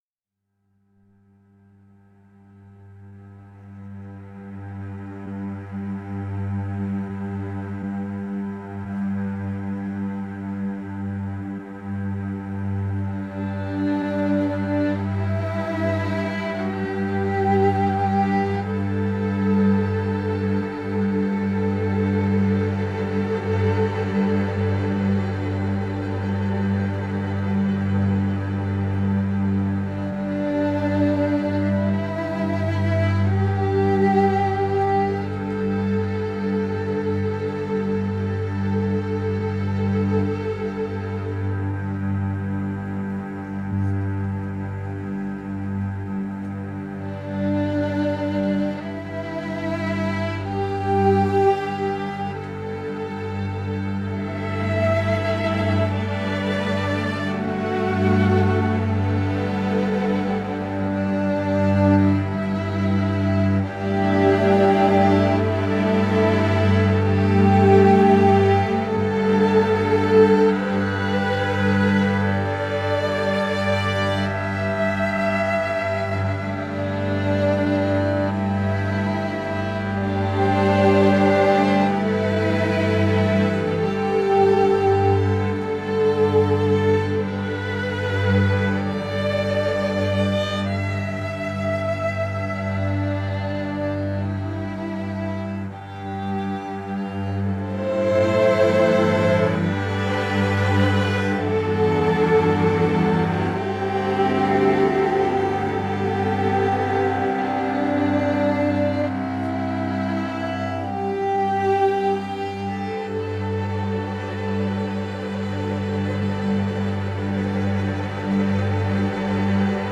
le jeune chanteur et songwriter